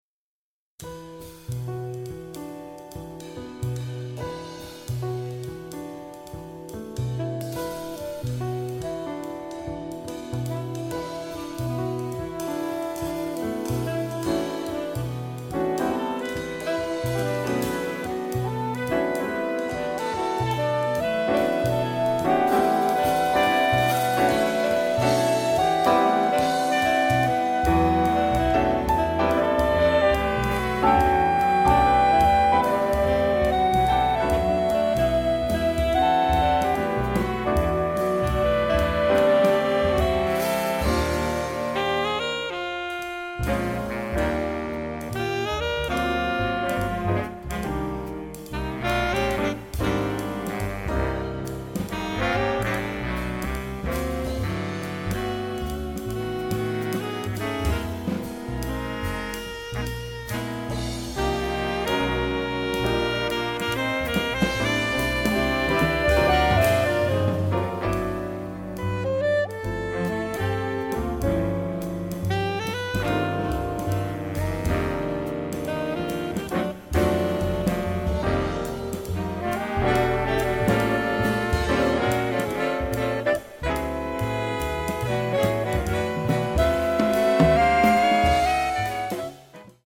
Voicing: Saxophone Quintet